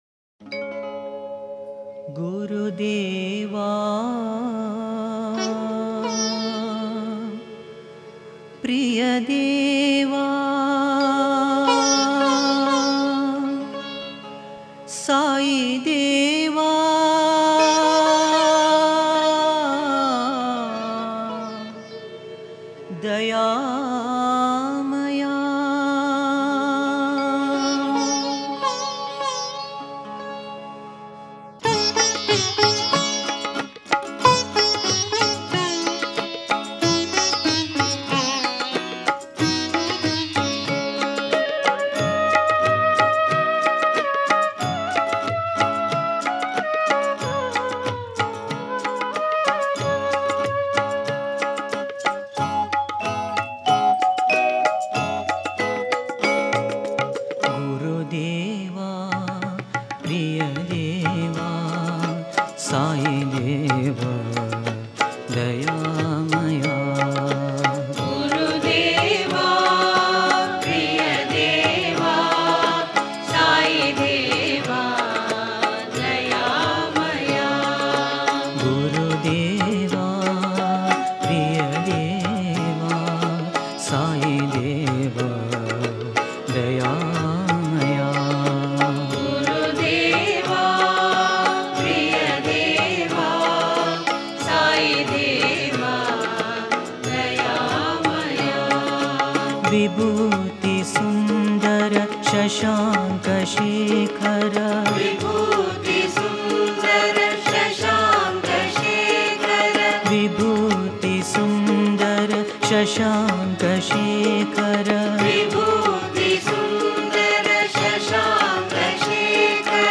Author adminPosted on Categories Guru Bhajans